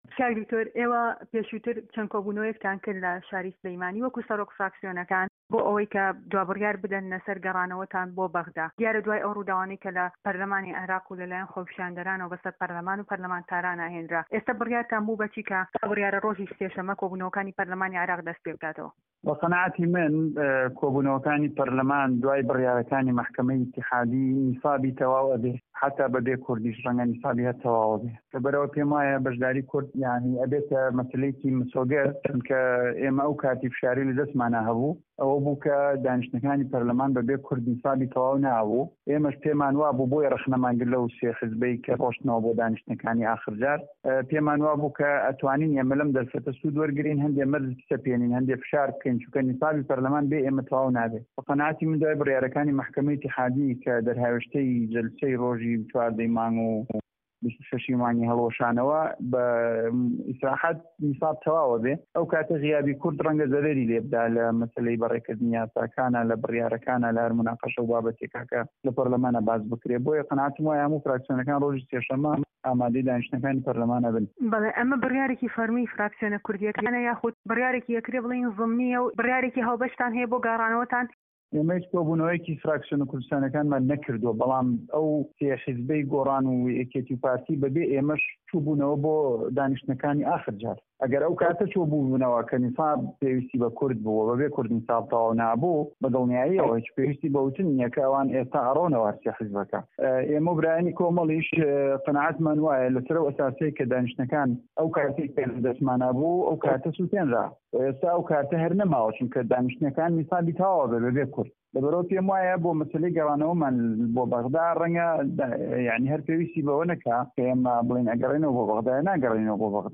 Interview with Dr. Musana Amin